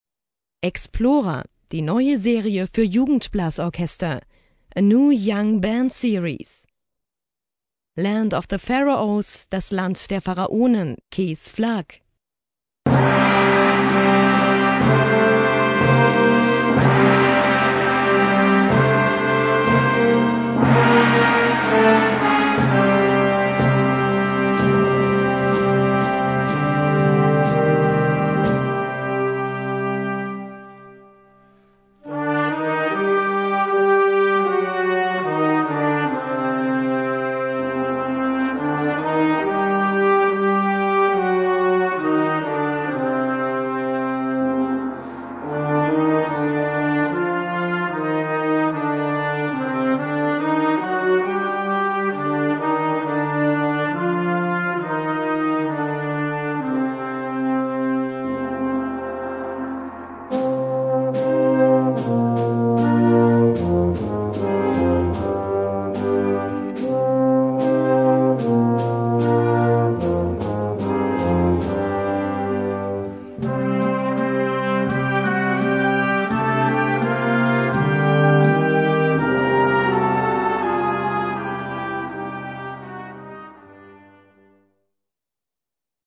Gattung: Konzertstück für Jugendblasorchester
Besetzung: Blasorchester